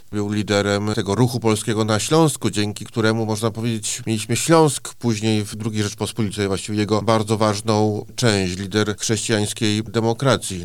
O tym, z czego szczególnie zasłynął nasz bohater, mówi zastępca prezesa IPN dr Mateusz Szpytma: